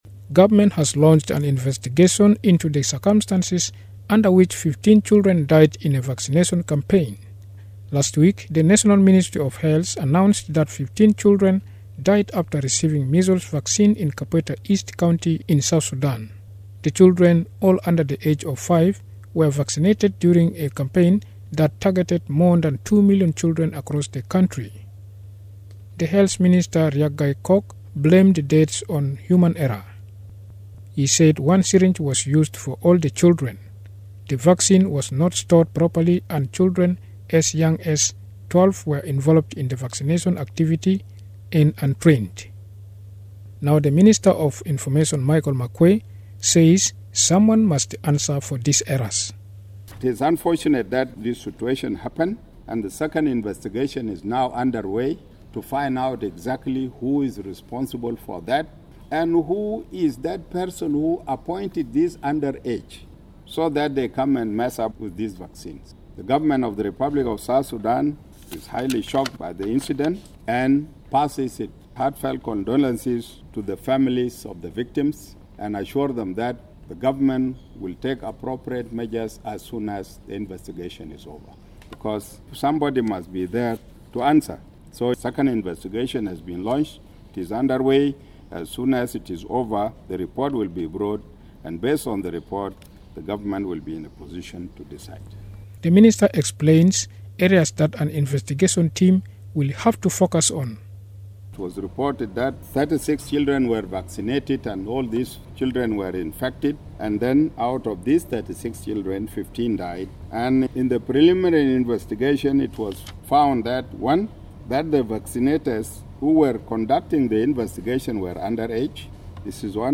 Information Minister Michael Makuei says the government will take a decision after the findings of the second investigation team.